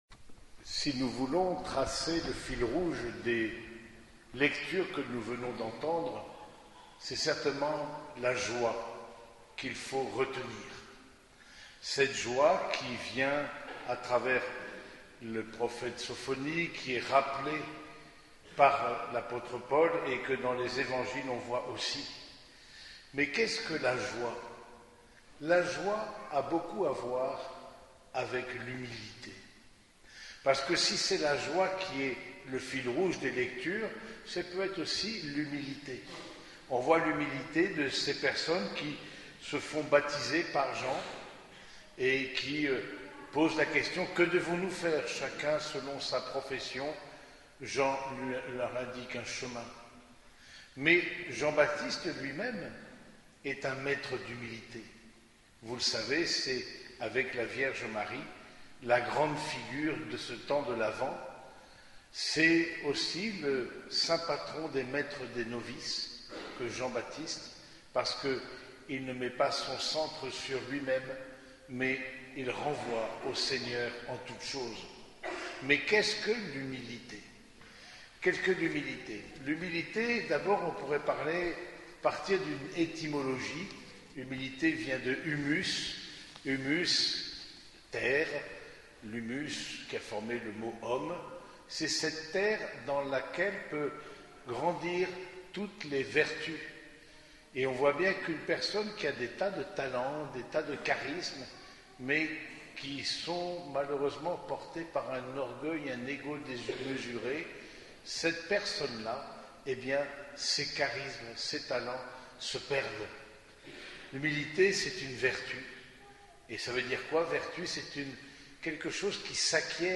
Homélie du troisième dimanche de l'Avent